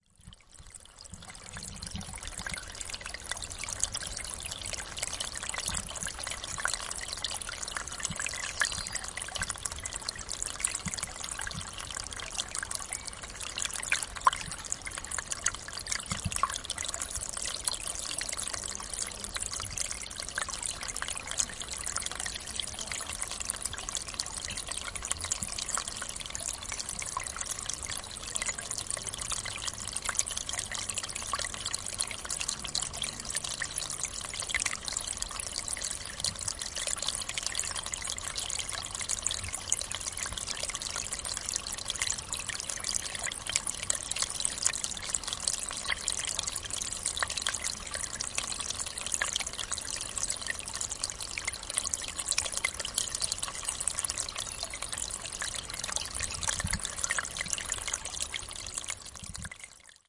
森林 " sfx amb 森林之春 afternon01
描述：重的小森林气氛。春天，下午。位置：布拉迪斯拉发 斯洛伐克Koliba由Olympus LS11录制
标签： 鸟鸣声 氛围 环境 森林 气氛 春天 自然 现场记录 一般噪音 大气
声道立体声